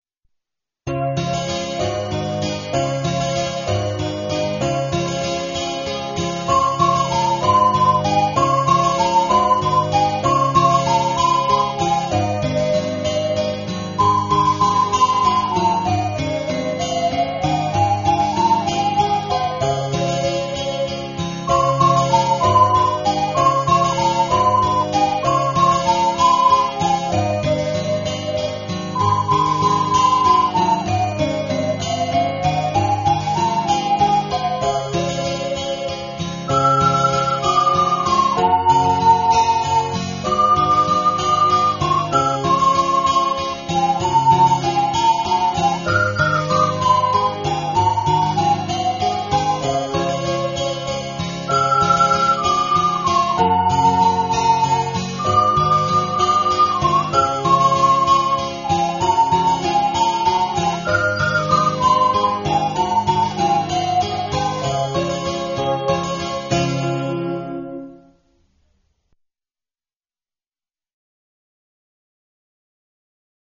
オカリナ曲名 ケーナ・サンポーニャ曲名